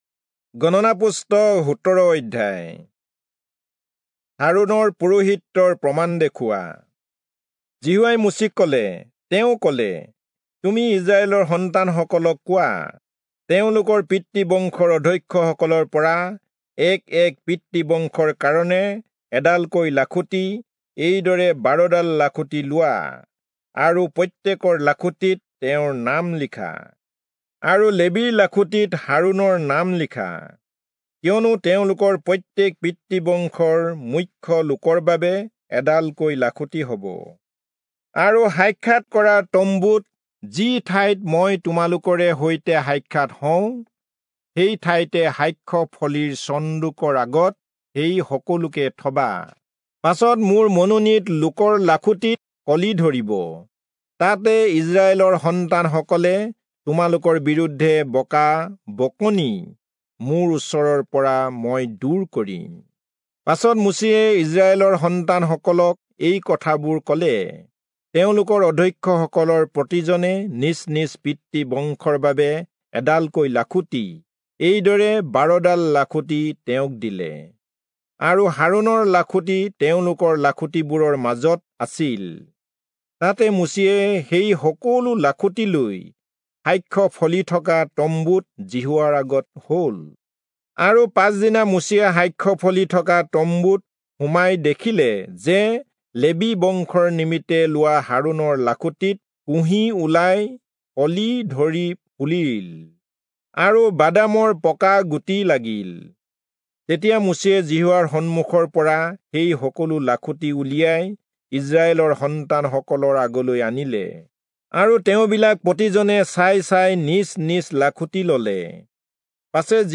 Assamese Audio Bible - Numbers 28 in Knv bible version